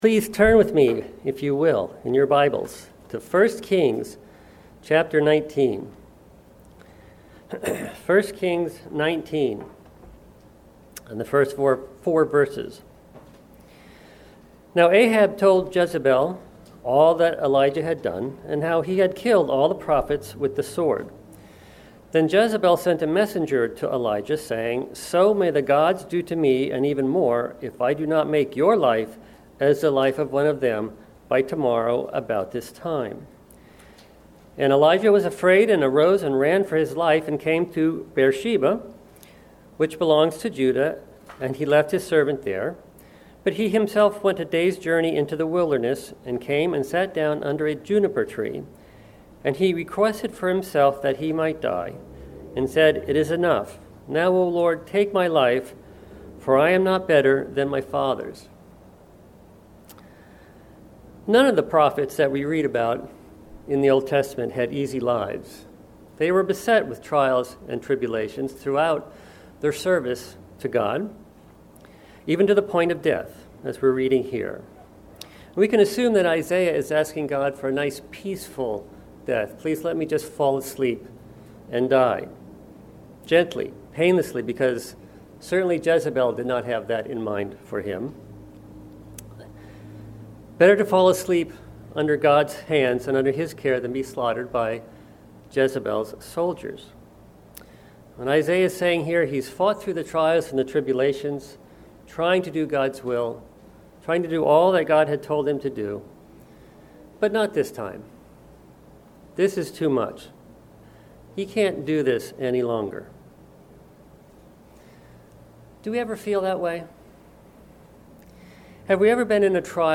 Given in Delmarva, DE